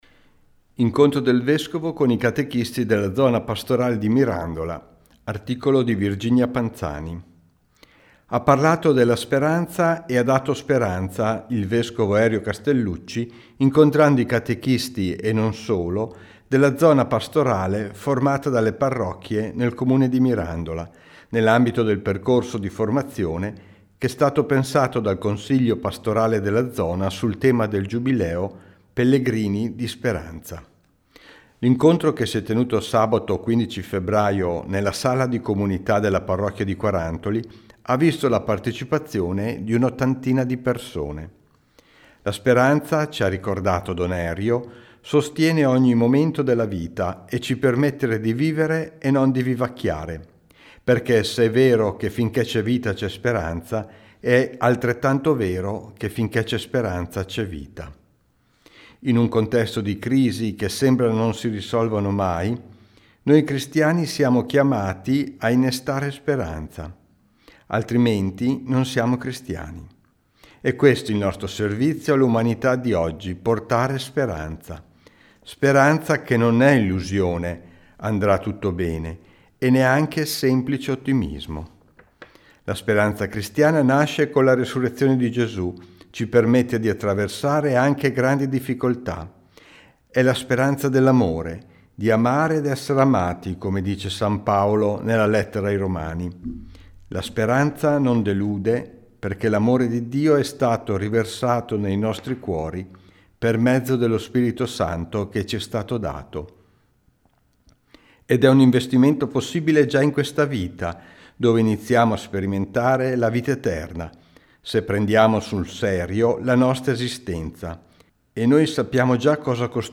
Lo scorso 15 febbraio, nella sala di comunità della parrocchia di Quarantoli, il vescovo Erio Castellucci ha guidato l’incontro di formazione per i catechisti della zona pastorale di Mirandola sul tema dell’Anno giubilare, “Pellegrini di speranza”
L’incontro, che si è tenuto sabato 15 febbraio, nella sala di comunità della parrocchia di Quarantoli, ha visto la partecipazione di un’ottantina di persone.